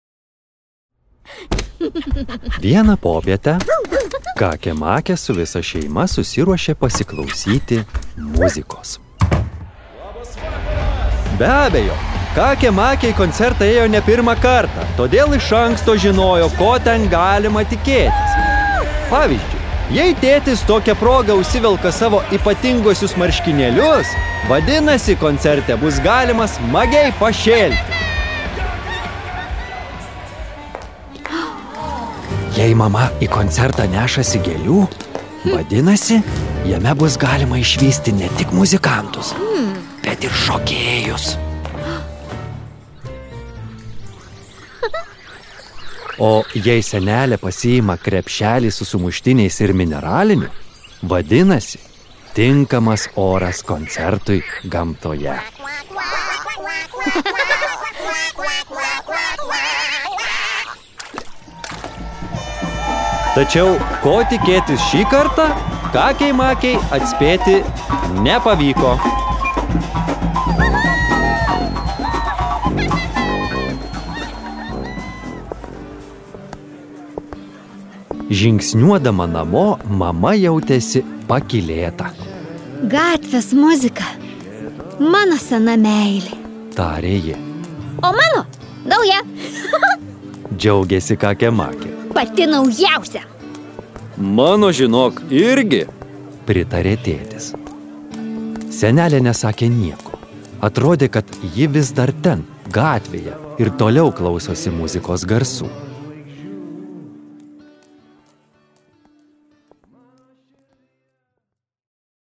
Kakė Makė ir koncerto diena | Audioknygos | baltos lankos